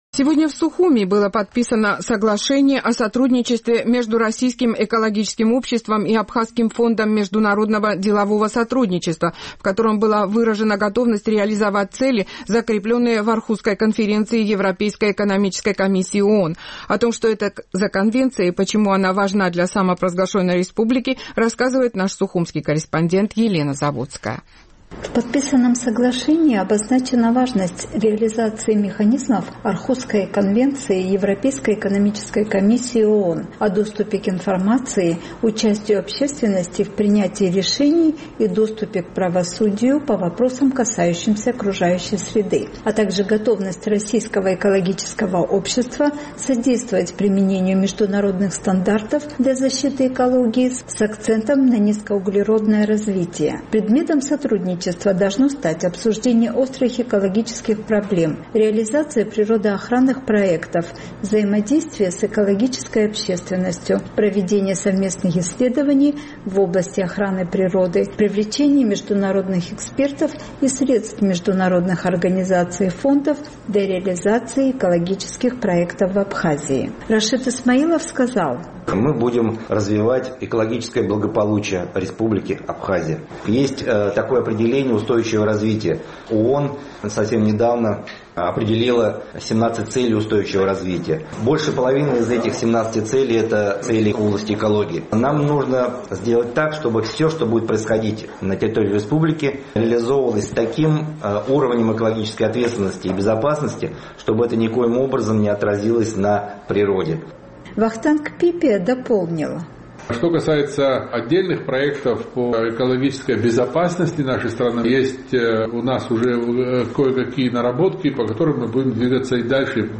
ответили на вопросы журналистов